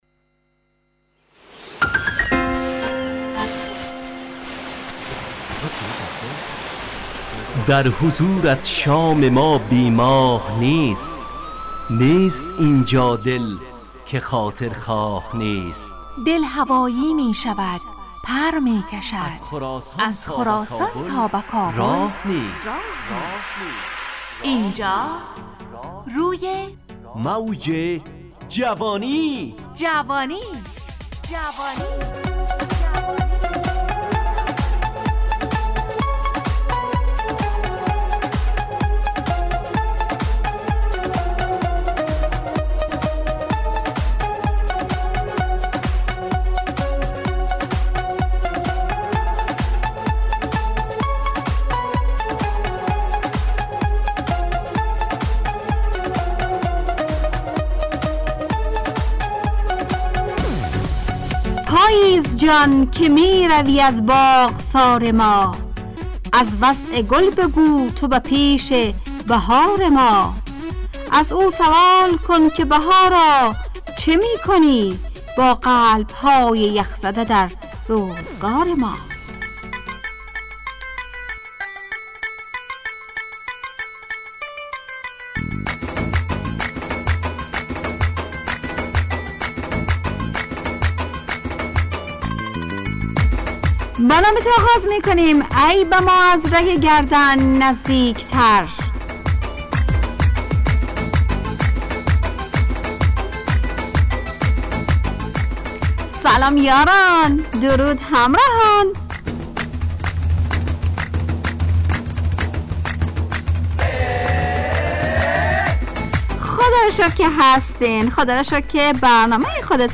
از شنبه تا پنجشنبه ازساعت 4:45 الی5:55 به وقت افغانستان، طرح موضوعات روز، وآگاهی دهی برای جوانان، و.....بخشهای روزانه جوان پسند. همراه با ترانه و موسیقی مدت برنامه 70 دقیقه .